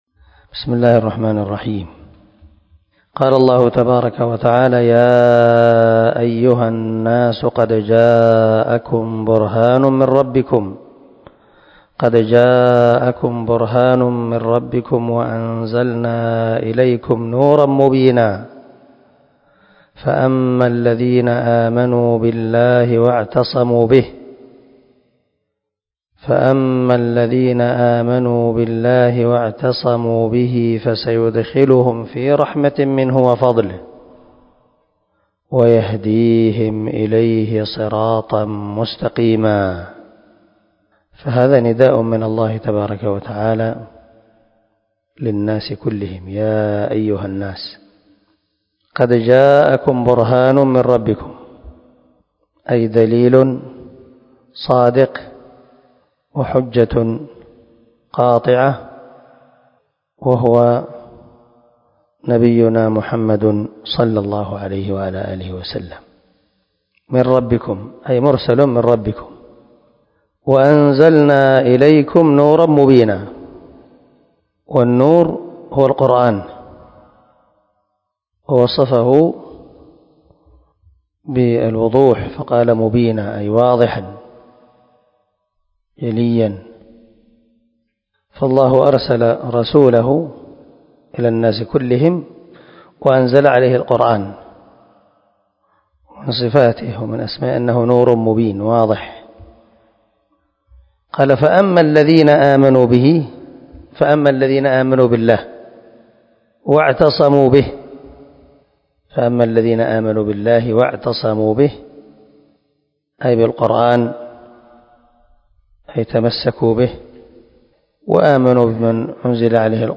332الدرس 100 تفسير آية ( 174 - 175 )من سورة النساء من تفسير القران الكريم مع قراءة لتفسير السعدي